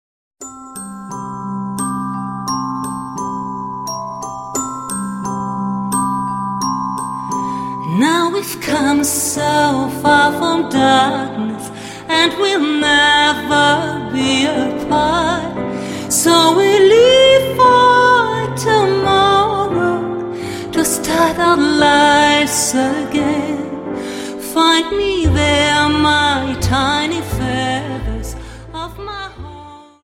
Dance: Slow Waltz Song